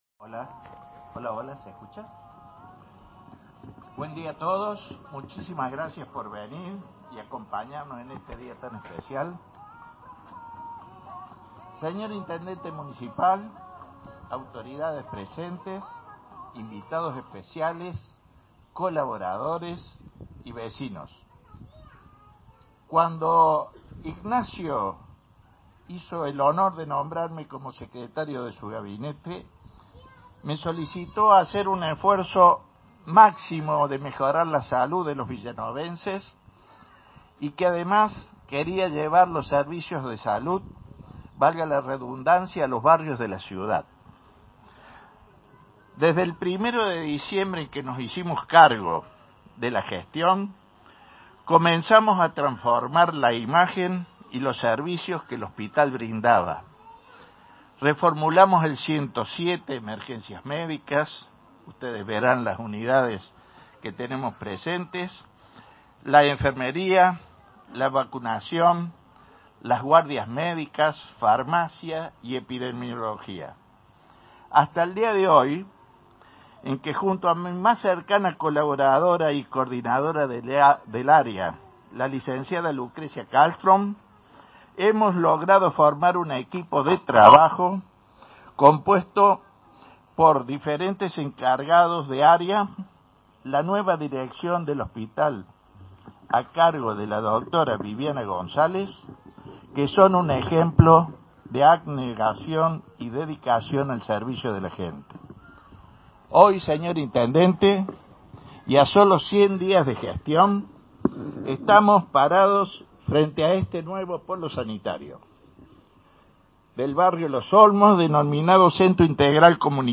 Durante la mañana del miércoles, se inauguró el Centro Integral Comunitario en el barrio Los Olmos de la vecina ciudad de Villa Nueva.
Audio: palabras del secretario de Salud y Desarrollo Social, Juan José Vagnola: